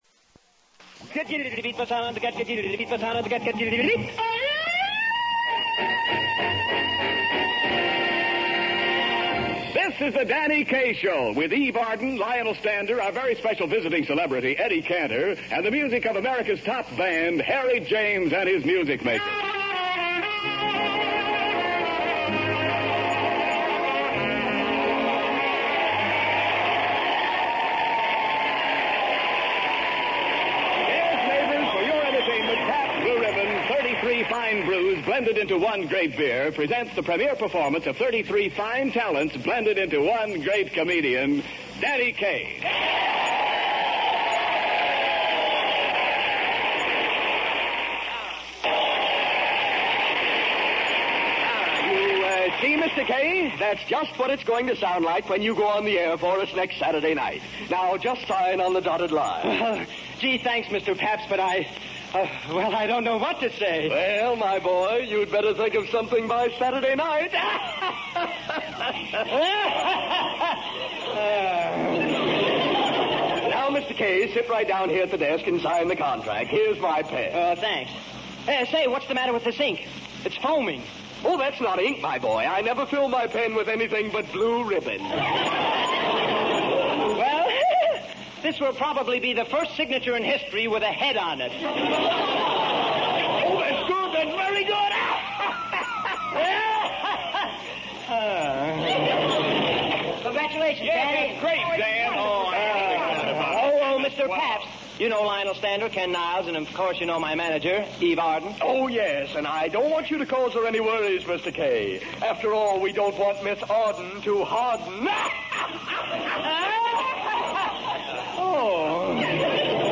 old time radio